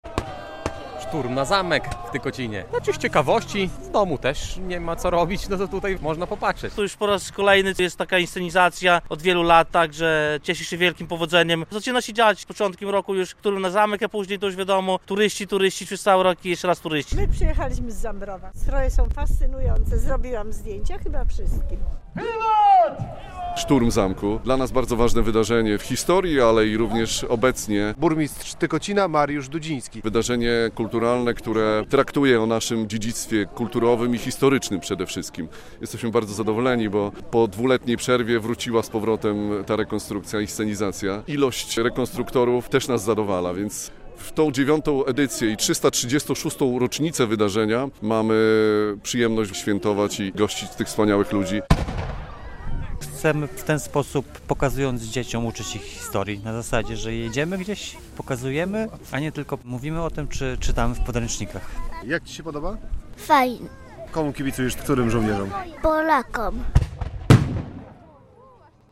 Szturm Zamku w Tykocinie - inscenizacja wydarzeń z 1657 roku [zdjęcia, wideo]